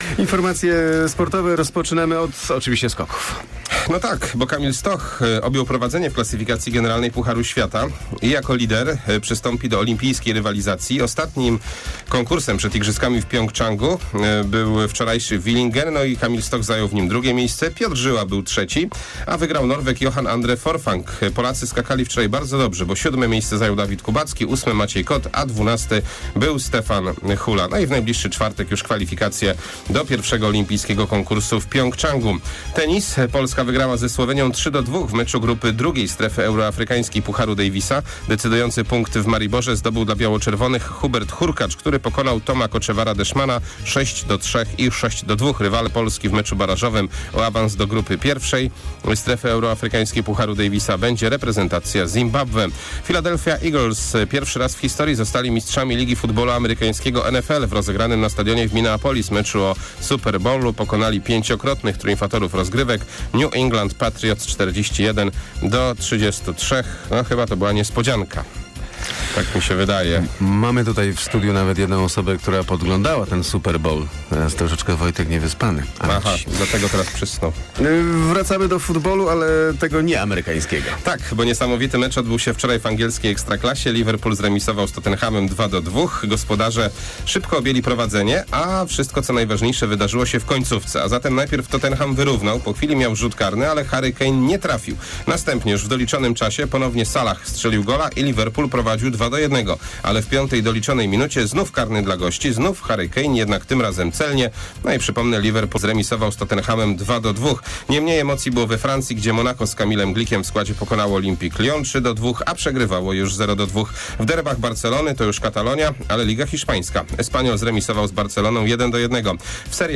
05.02 serwis sportowy godz. 7:45